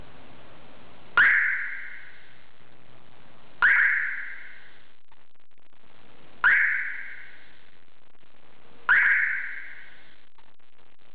【試聴】標準サイレン
siren_p2.wav